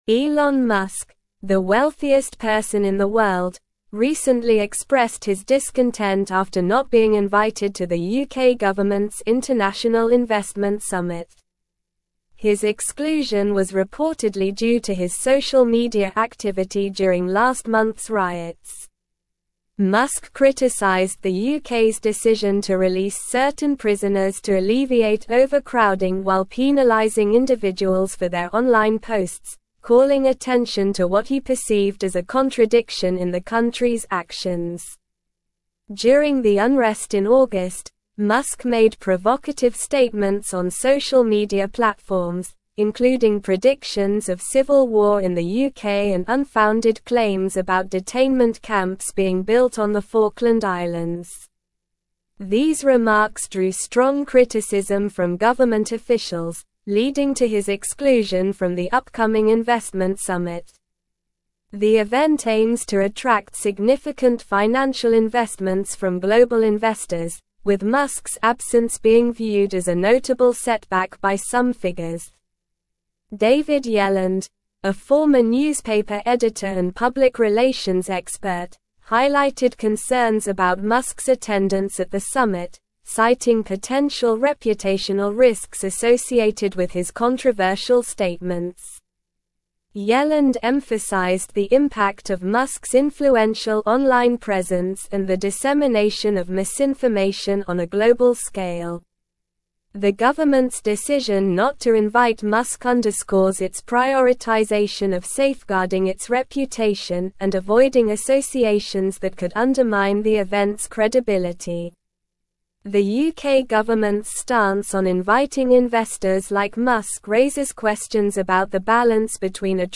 Slow
English-Newsroom-Advanced-SLOW-Reading-Elon-Musk-Excluded-from-UK-Investment-Summit-Over-Posts.mp3